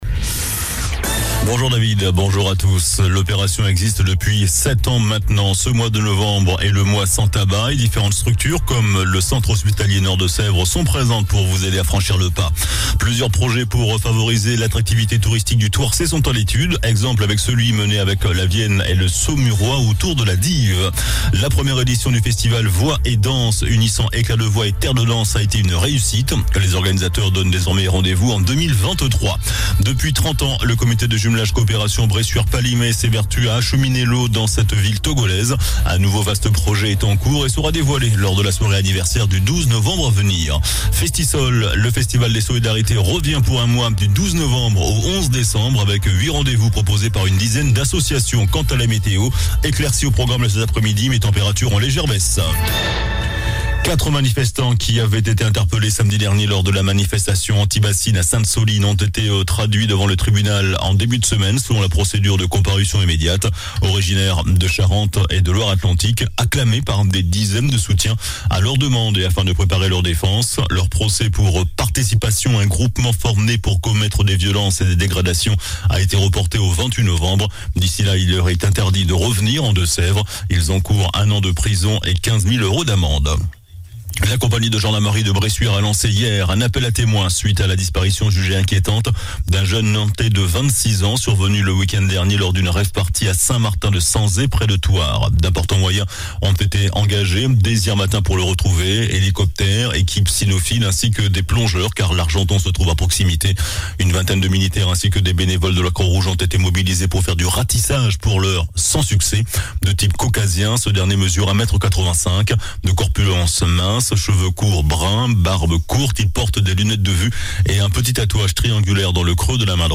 JOURNAL DU MERCREDI 02 NOVEMBRE ( MIDI )